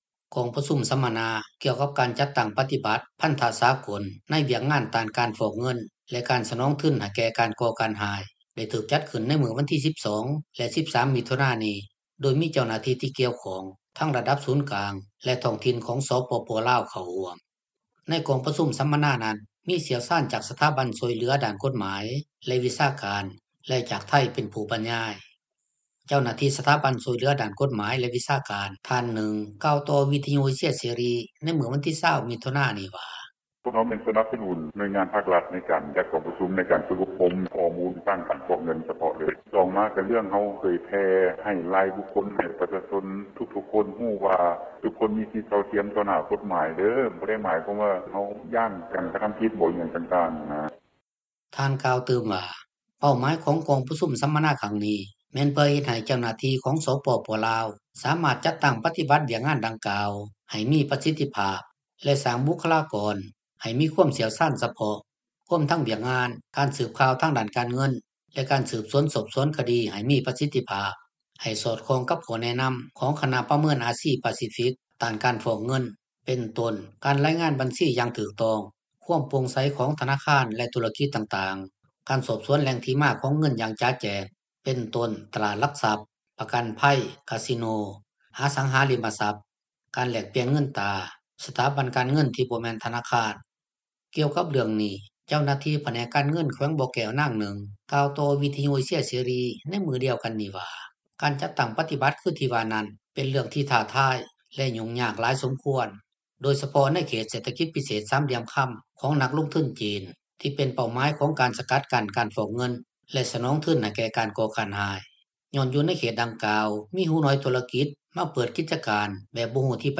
ເຈົ້າໜ້າທີ່ສະຖາບັນຊ່ວຍເຫລືອດ້ານກົດໝາຍ ແລະວິຊາການ ທ່ານນຶ່ງກ່າວຕໍ່ວິທຍຸເອເຊັຍເສຣີ ໃນມື້ວັນທີ 20 ມິຖຸນານີ້ວ່າ:
ກ່ຽວກັບເລື່ອງທີ່ວ່ານີ້ ປະຊາຊົນຜູ້ນຶ່ງ ຢູ່ນະຄອນຫລວງວຽງຈັນ ເວົ້າຕໍ່ວິທຍຸເອເຊັຍເສຣີ ໃນມື້ດຽວກັນນີ້ວ່າ ສາເຫດທີ່ເຮັດໃຫ້ປະເທດລາວ ຍັງມີຄວາມສ່ຽງສູງ ເລື່ອງການຟອກເງິນ ແລະການສະໜອງທຶນ ໃຫ້ແກ່ການກໍ່ການຮ້າຍນັ້ນ ກໍຍ້ອນວ່າ ທາງການລາວ ແລະທະນາຄານທຸລະກິດ ບໍ່ເຂັ້ມງວດໃນການປະຕິບັດວຽກງານ ແລະກົດໝາຍຄຸ້ມຄອງ ບໍ່ຮັດກຸມປານໃດ ດັ່ງທີ່ທ່ານກ່າວວ່າ: